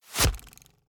Free Fantasy SFX Pack
Bow Attacks Hits and Blocks
Bow Blocked 3.ogg